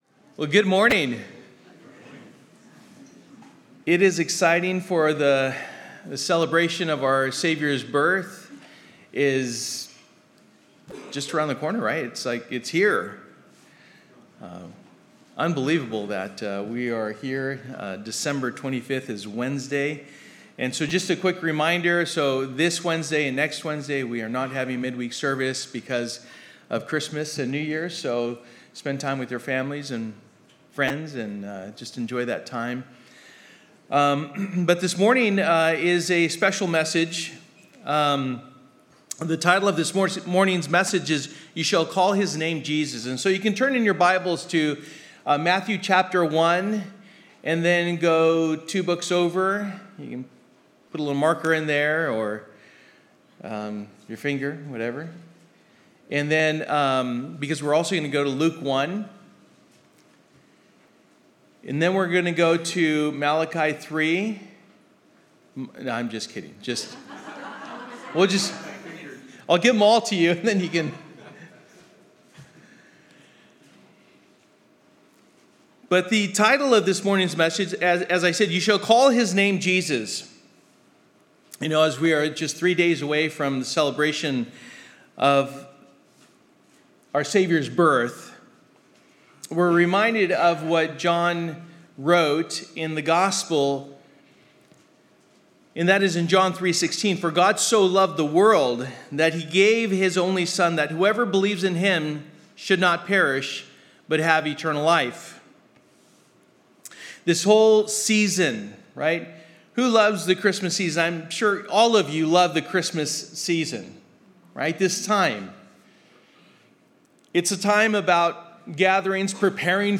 Christmas Passage: Matthew 1:18-25, Luke 1:26-38 Service: Sunday Morning